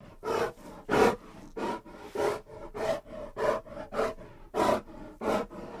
Kodiak Bear Breathing